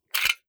fps_project_1/45 ACP 1911 Pistol - Magazine Unload 002.wav at fc29636ee627f31deb239db9fb1118c9b5ec4b9f - fps_project_1 - Gitea: Git with a cup of tea